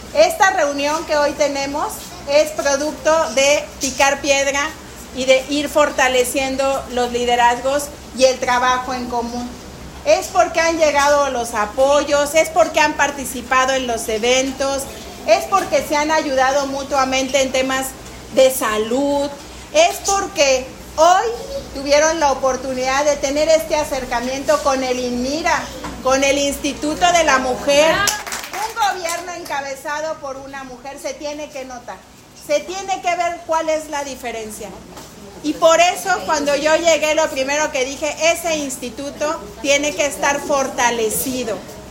Lorena Alfaro, presidenta municipal